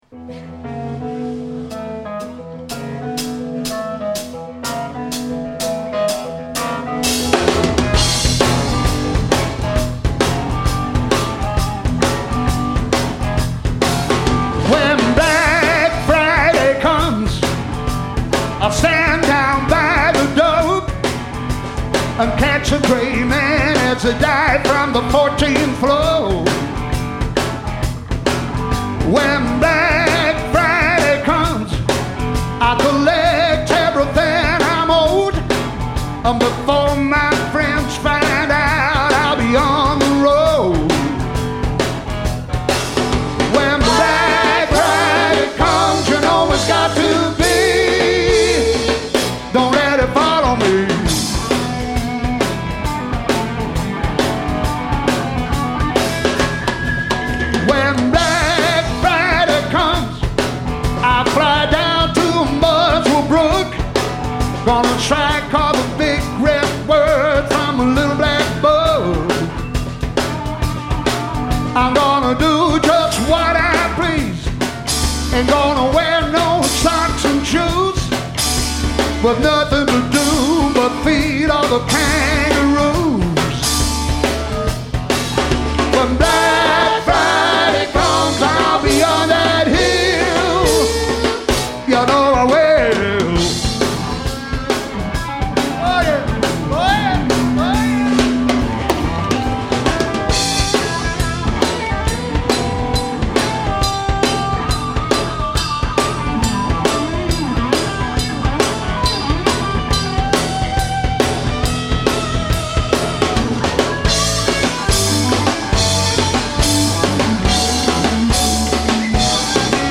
all songs recorded live 2009